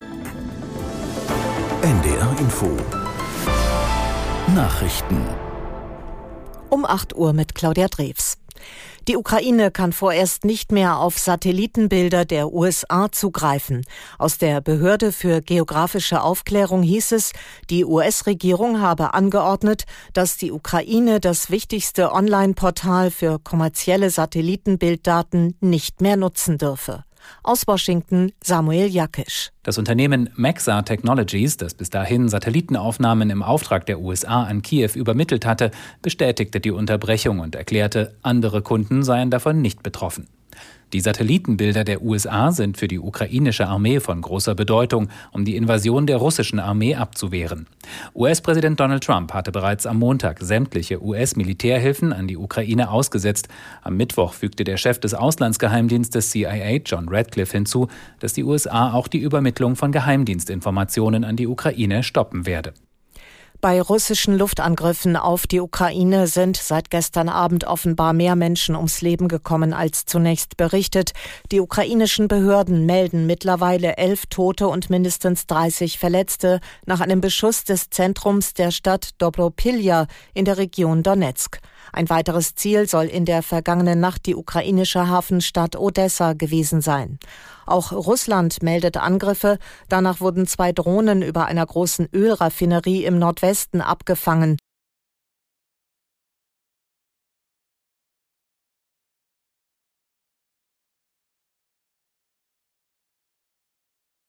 Nachrichten - 08.03.2025